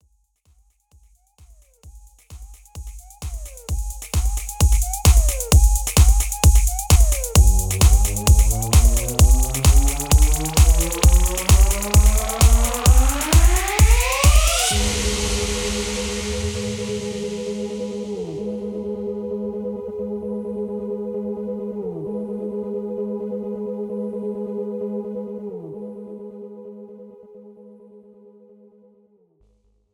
This is an instrumental backing track cover.
Key – F# / Gb
Without Backing Vocals
No Fade